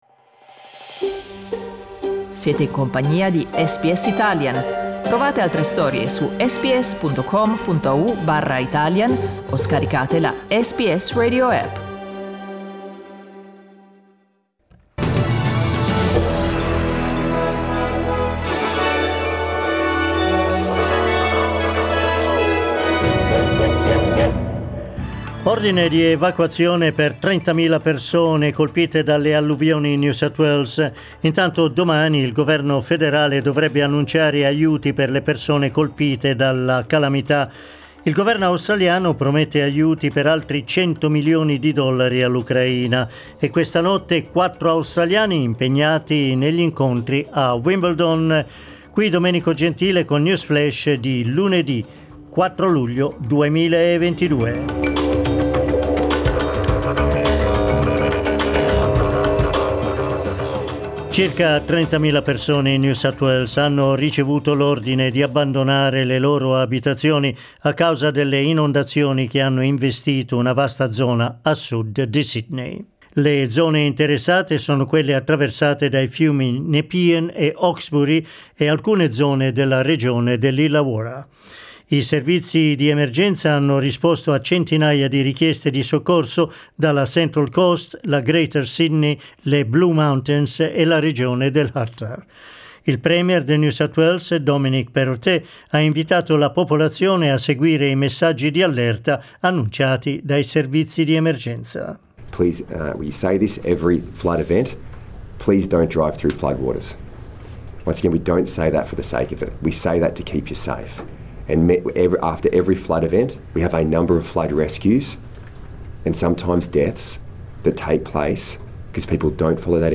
News flash lunedì 4 luglio 2022